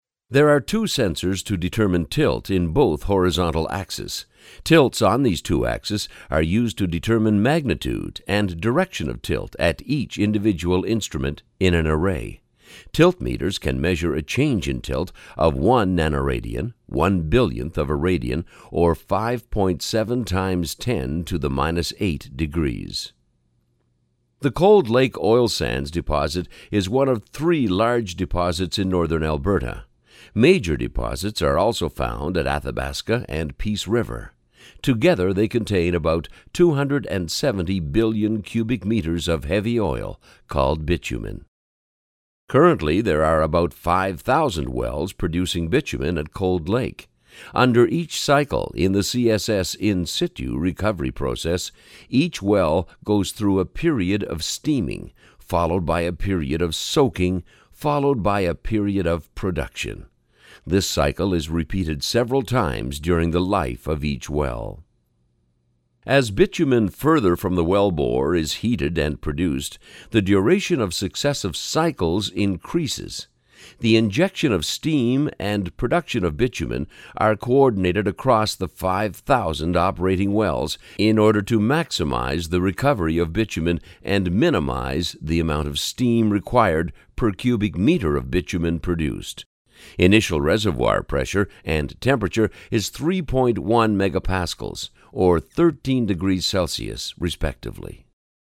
Experienced professional voiceovers, Canadian, American, guaranteed, free auditions
Sprechprobe: eLearning (Muttersprache):